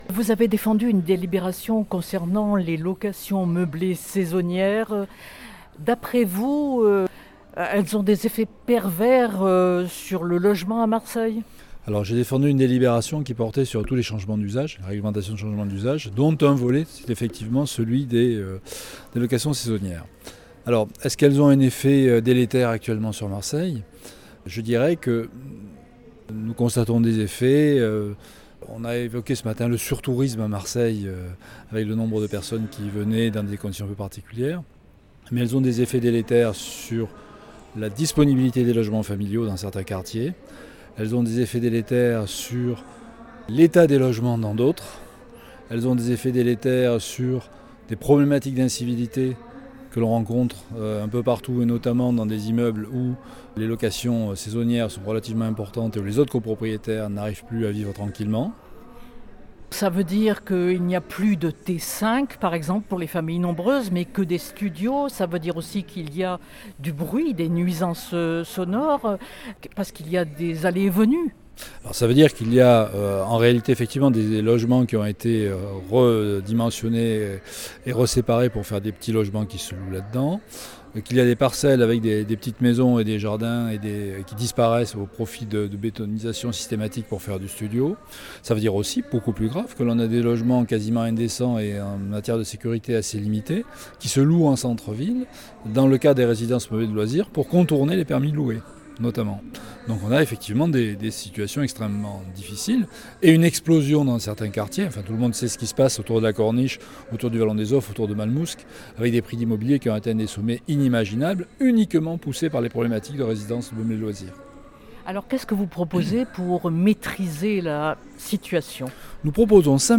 Politique du logement à Marseille. Entretien avec Patrick Amico : la Ville met fin aux excès des locations saisonnières…
La réduction de 5 à 1 du nombre de résidences secondaires autorisées par propriétaire à la location touristique sans compensation a été votée lors du dernier conseil municipal. Entretien avec Patrick Amico, adjoint au maire de Marseille en charge de la politique du logement et de la lutte contre l’habitat indigne.